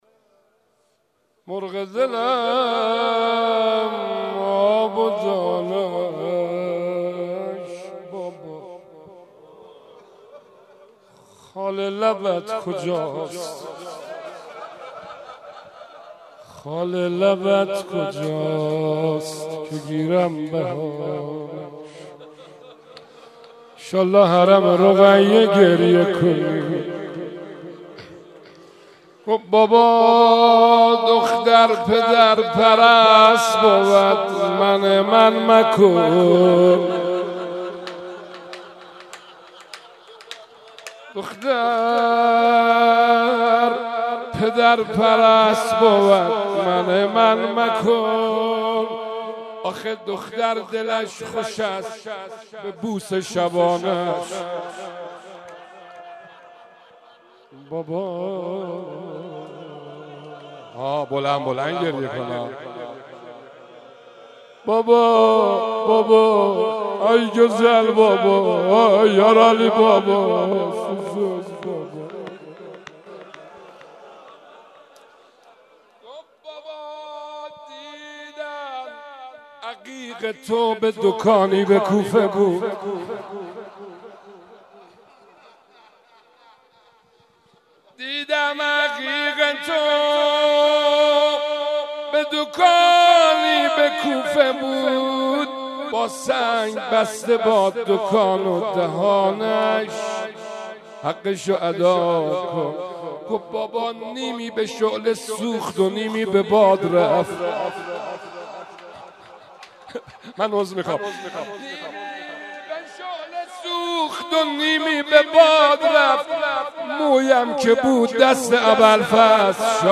03.rozeh2.mp3